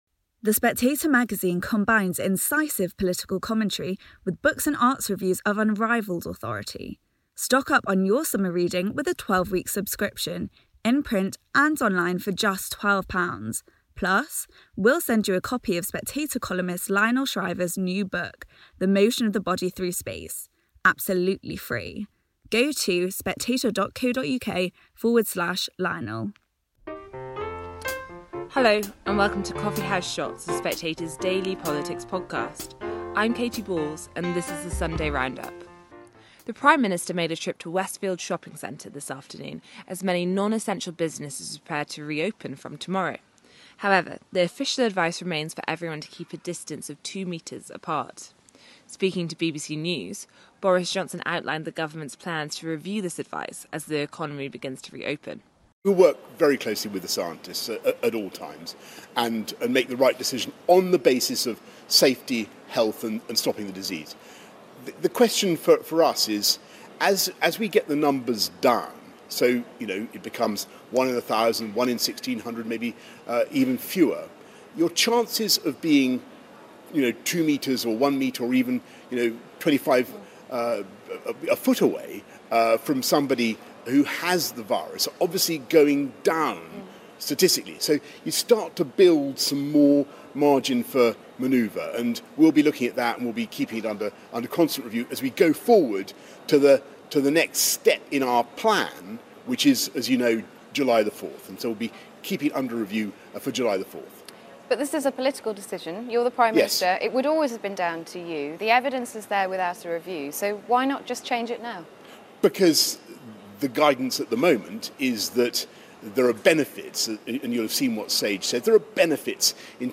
Katy Balls hosts today's essential interviews roundup, which today features Boris Johnson, Rishi Sunak, Nick Thomas-Symonds and David Lammy.